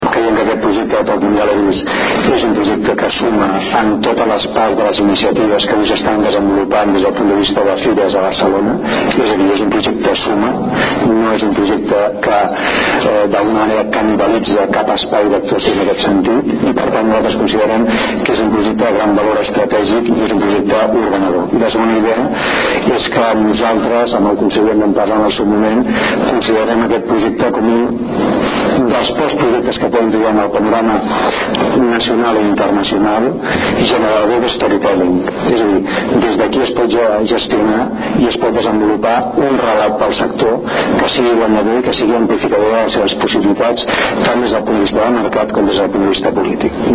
Declaracions conseller Mascarell